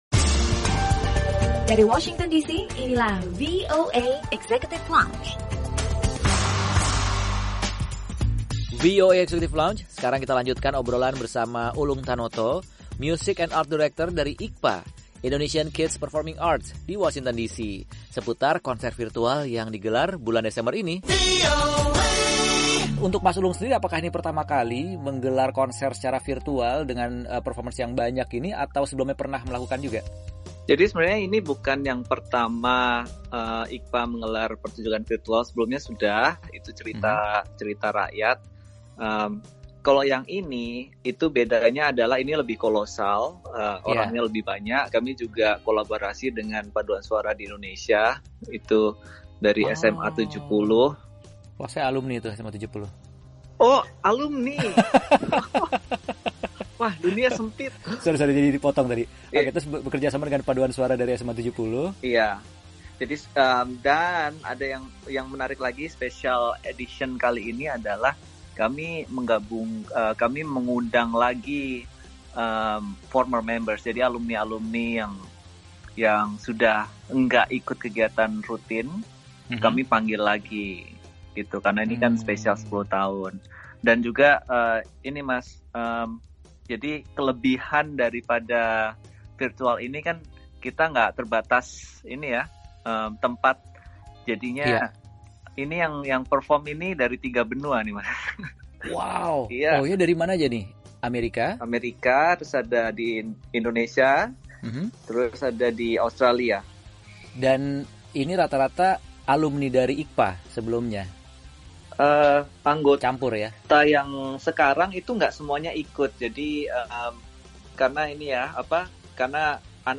Lanjutan obrolan